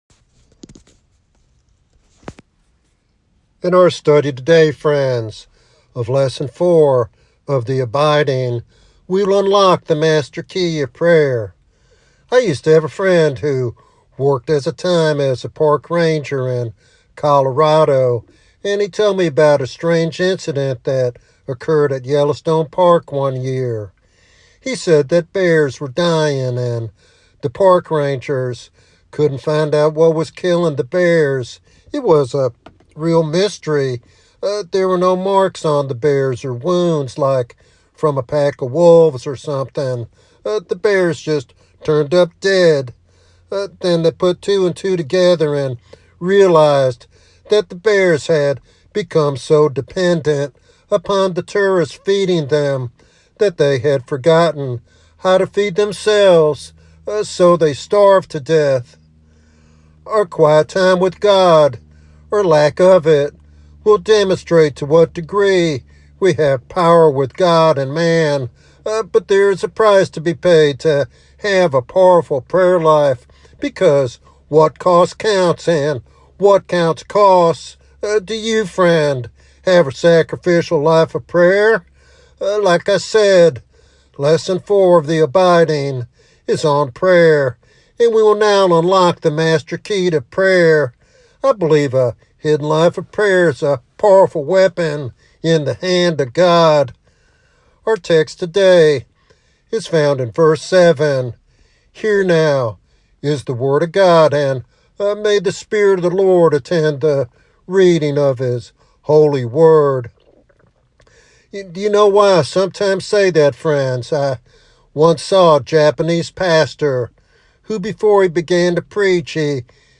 This teaching encourages listeners to deepen their faith and experience the transformative power of abiding prayer.